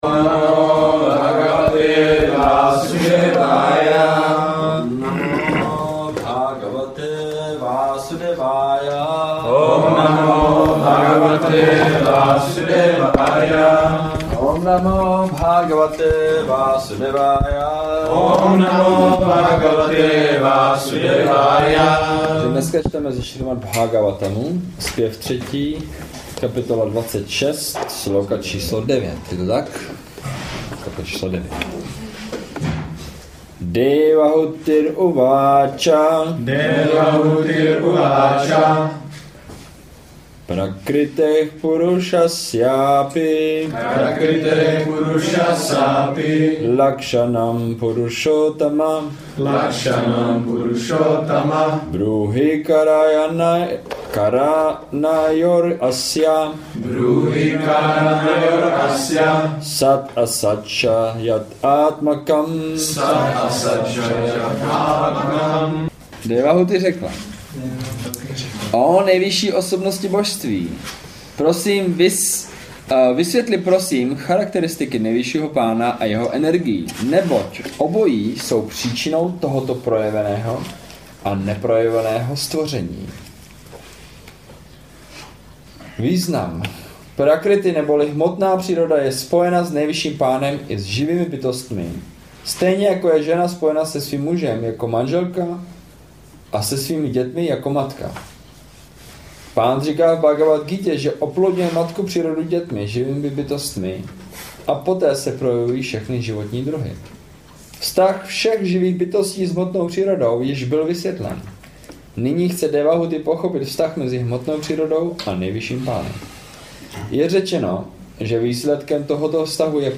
Přednášky z roku 2016